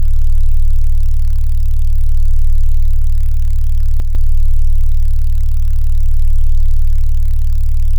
4.0秒の時点で15msの位相歪みを導入しました。